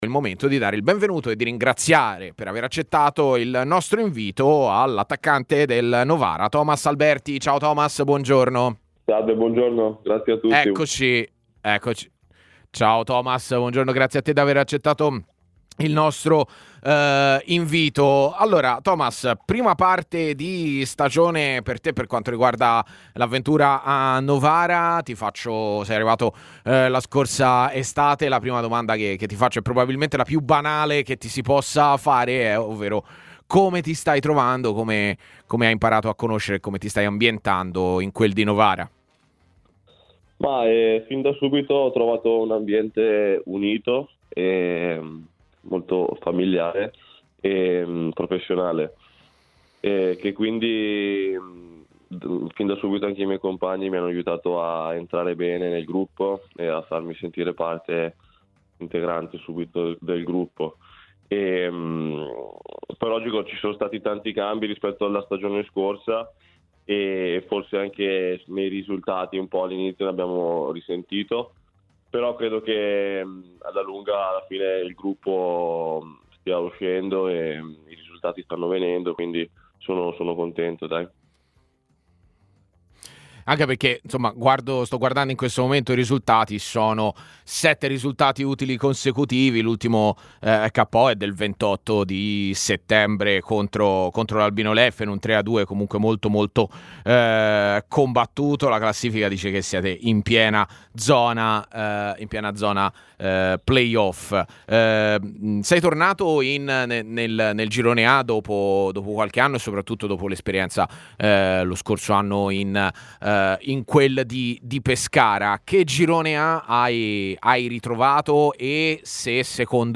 trasmissione in onda su TMW.